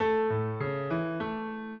piano
minuet7-8.wav